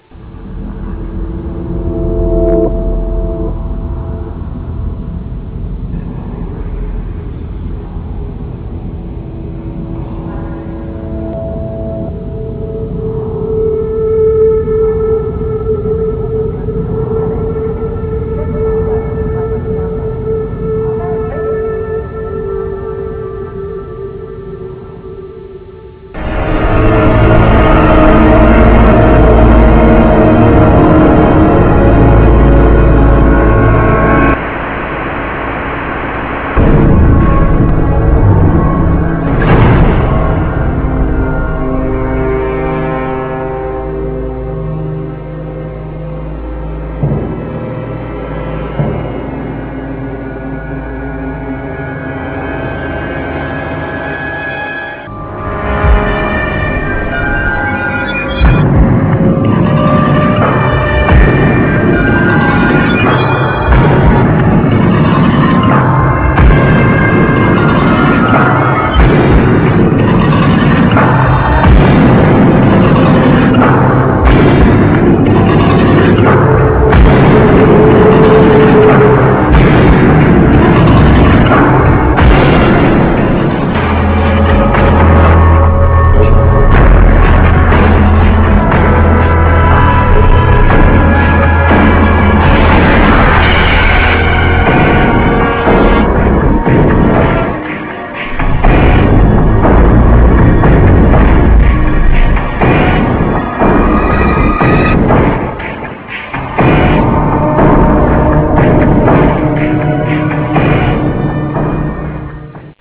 Musica elettronica al cubo.
Original track music